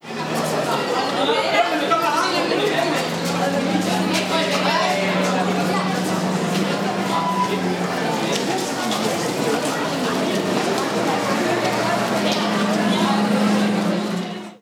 Adolescentes saliendo del instituto (voces y gritos)
Sonidos: Gente
Sonidos: Acciones humanas
Sonidos: Voz humana